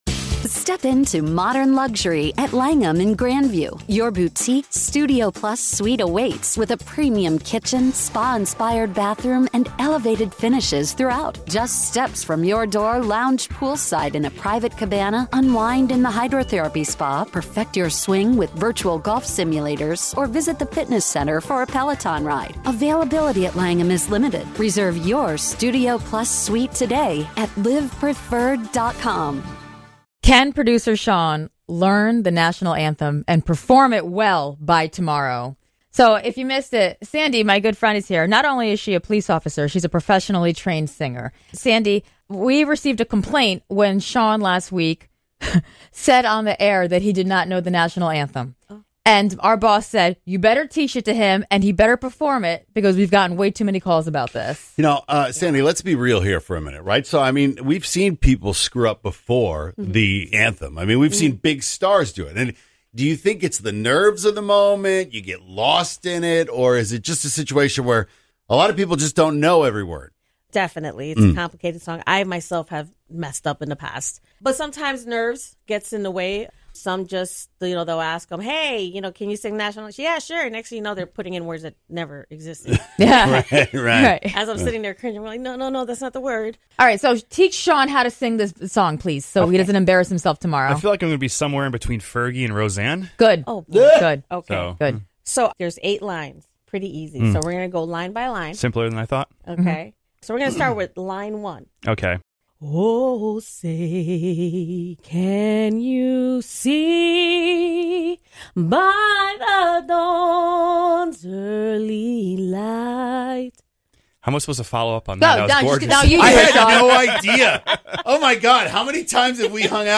Listen to his lesson, do you think he has a shot at succeeding tomorrow?